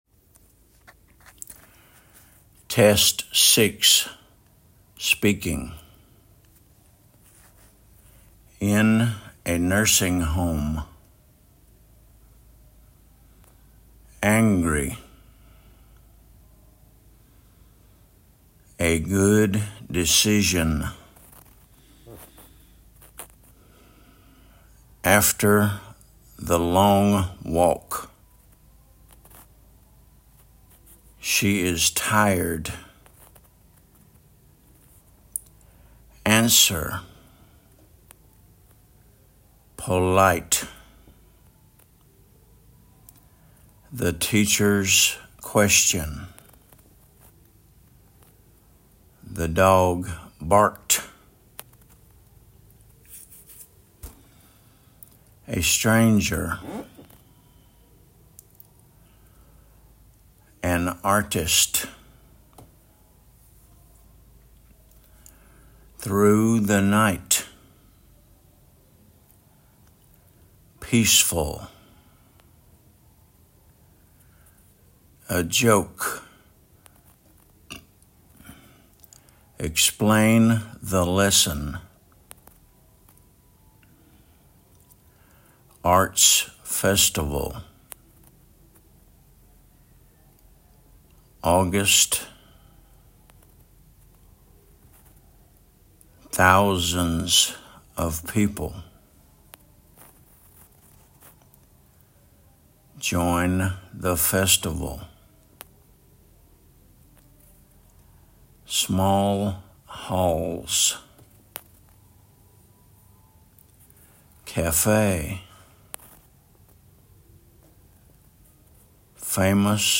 in a nursing home /ɪn ə ˈnɜːsɪŋ həʊm/
after the long walk /ˈɑːftə ðə lɒŋ wɔːk/
the dog barked /ðə dɒɡ bɑːkt/
arts festival /ɑːts ˈfɛstəvəl/
famous musicians /ˈfeɪməs mjuːˈzɪʃənz/